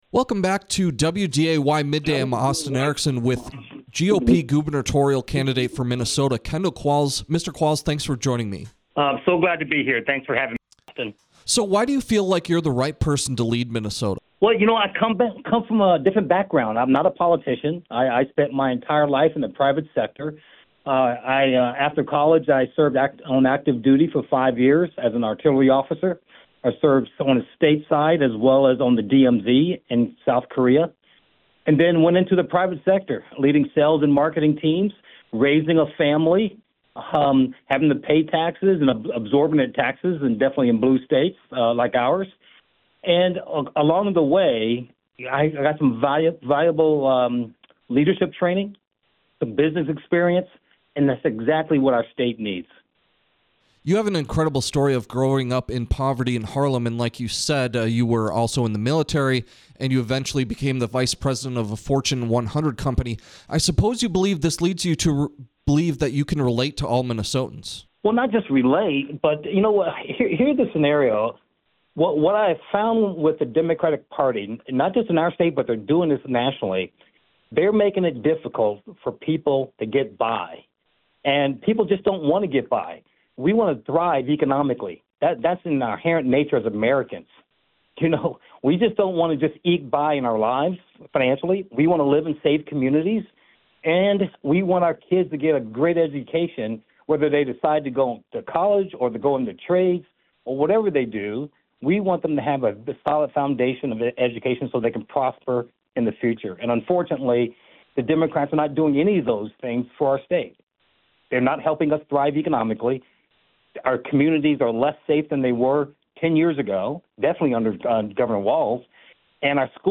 kendall-qualls-interview.mp3